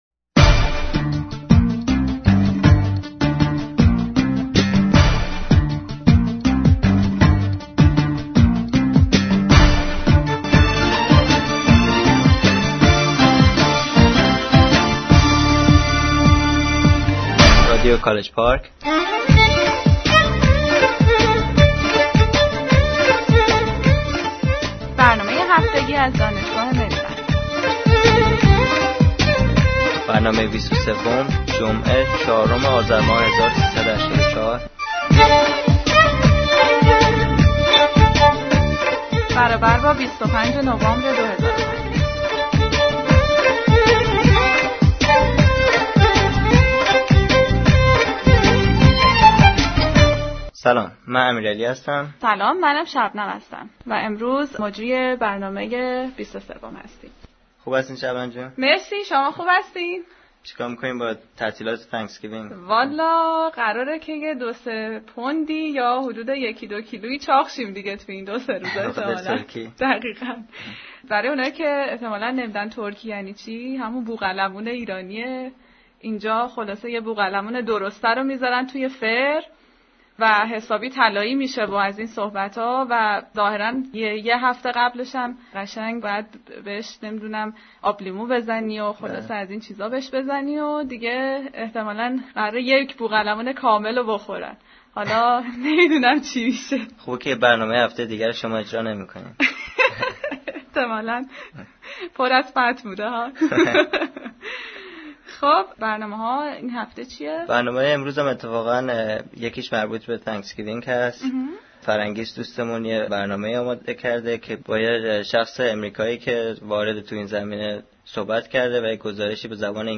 Lecture by Dr. Yarshater about Persian Language (English)